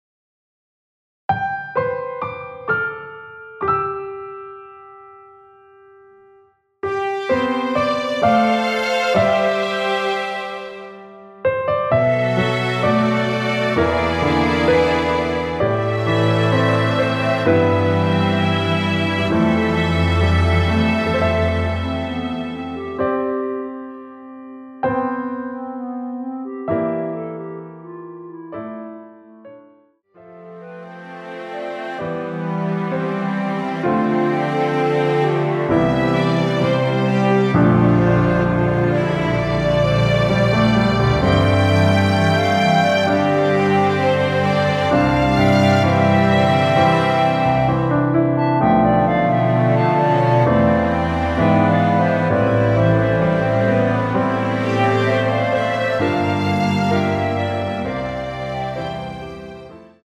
원키에서(-2)내린 멜로디 포함된 MR입니다.
앞부분30초, 뒷부분30초씩 편집해서 올려 드리고 있습니다.
중간에 음이 끈어지고 다시 나오는 이유는